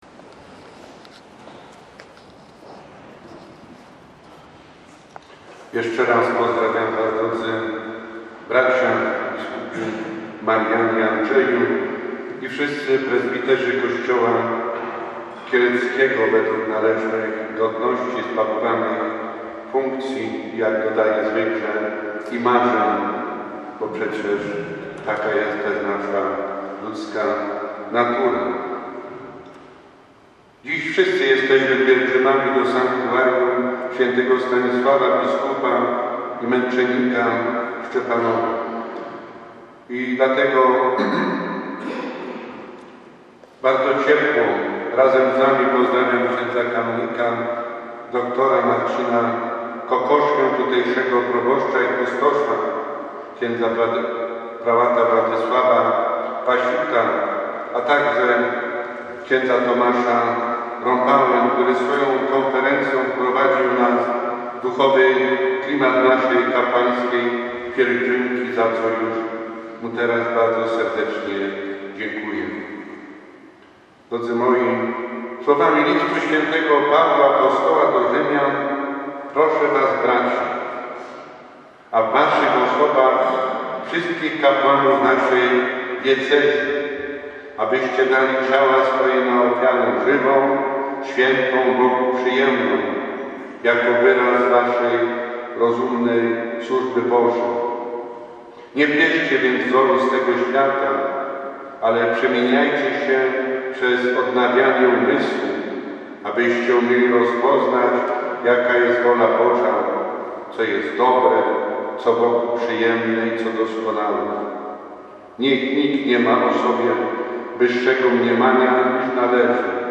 W sobotę, 12 października 2024 r. odbyła się Pielgrzymka Kapłanów Diecezji Kieleckiej do Sanktuarium św. Stanisława w Szczepanowie.
O bliskości z Bogiem, biskupem, prezbiterami i wiernymi mówił w homilii bp Jan Piotrowski.